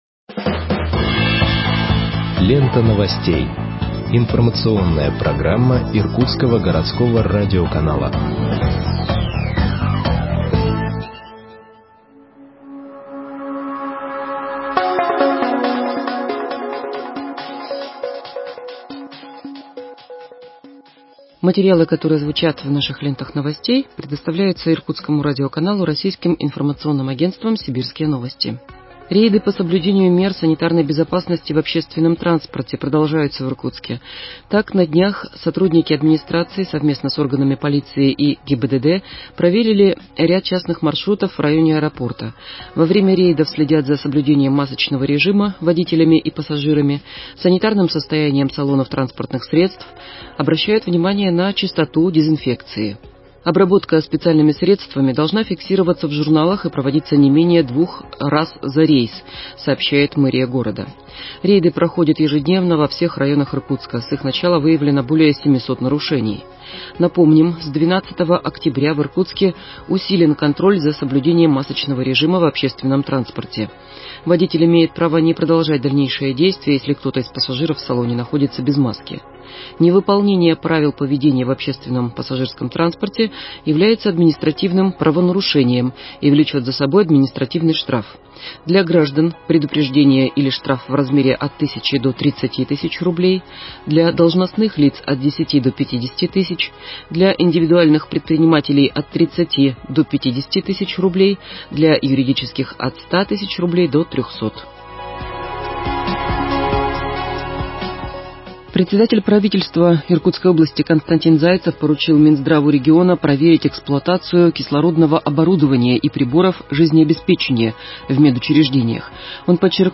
Выпуск новостей в подкастах газеты Иркутск от 05.11.2020